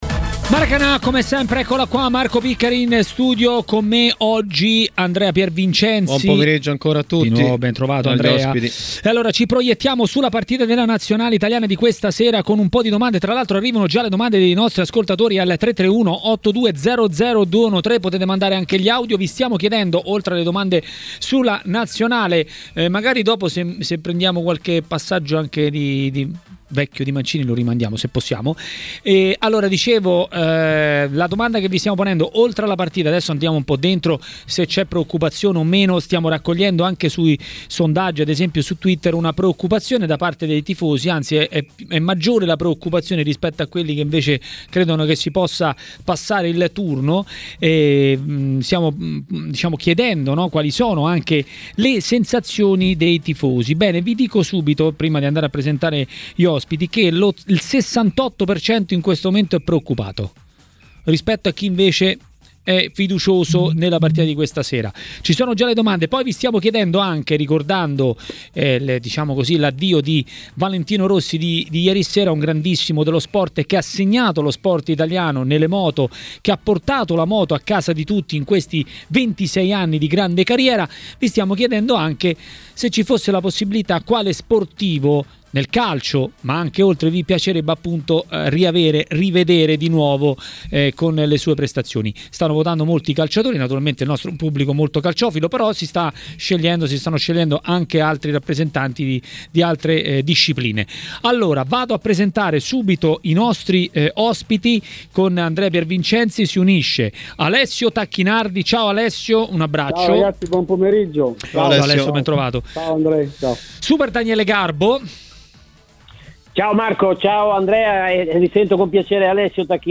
A parlare di Nazionale e non solo a Maracanà, nel pomeriggio di TMW Radio, è stato l'ex calciatore e tecnico Alessio Tacchinardi.